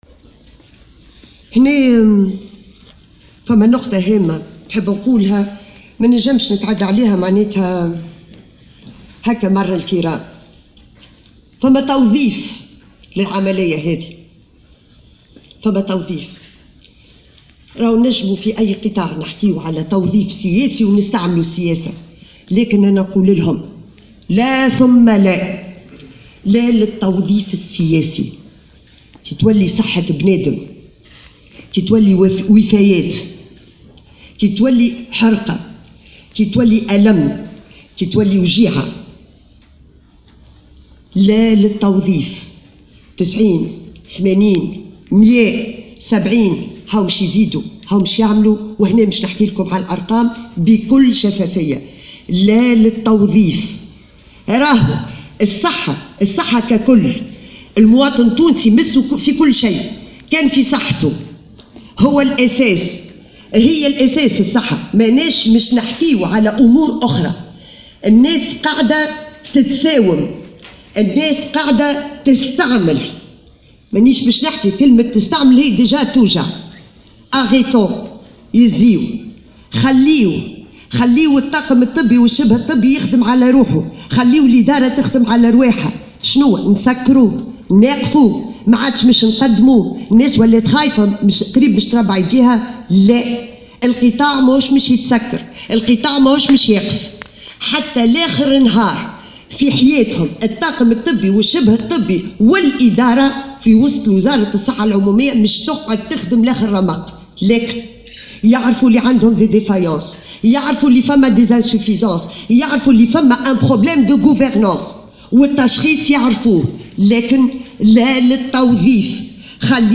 وطالبت في ندوة صحفية عقدتها اليوم بتونس العاصمة، الأطراف التي اتهمتهما بالتوظيف السياسي بترك المجال للمختصين للقيام بعملهم، وفق تعبيرها. وأقرّت بالخلل الموجود بمنظومة الصحة العموميّة، مشددة على أهمية استرجاع الثقة بين الإدارة والمواطن.